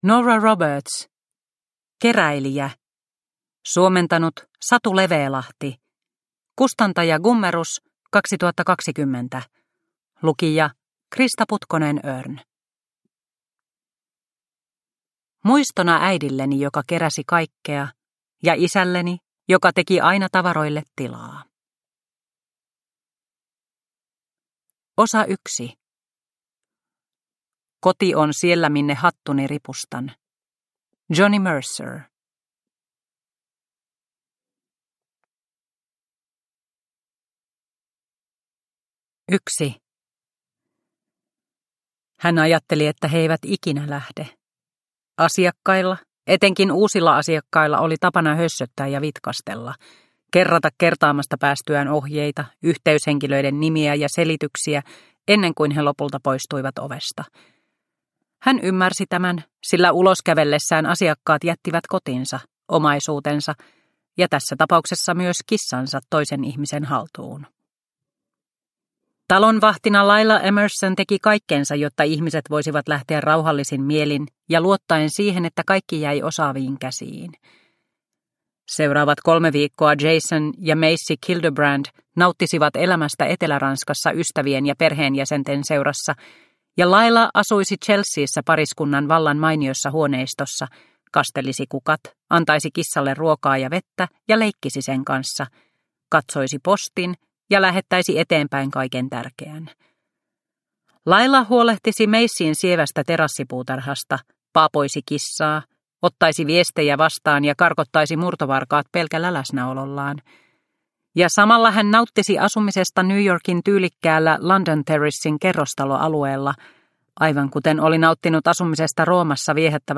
Keräilijä – Ljudbok – Laddas ner